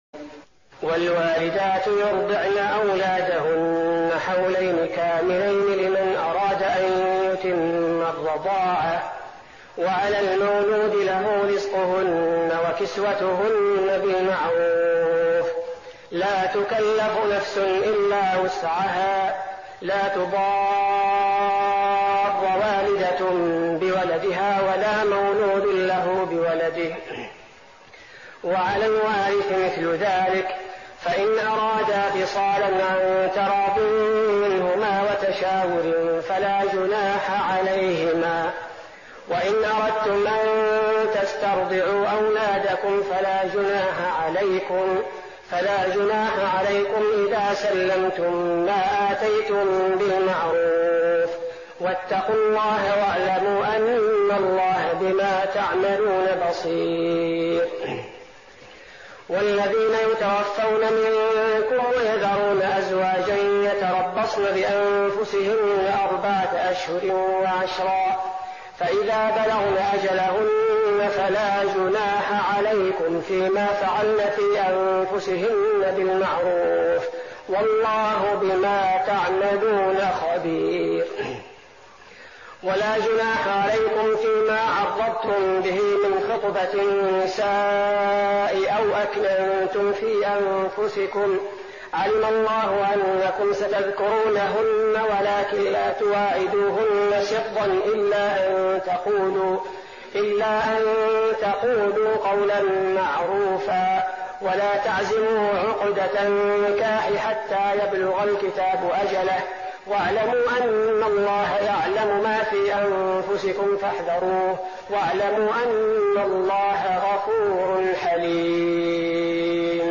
تهجد رمضان 1415هـ من سورة البقرة (233-257) Tahajjud night Ramadan 1415H from Surah Al-Baqara > تراويح الحرم النبوي عام 1415 🕌 > التراويح - تلاوات الحرمين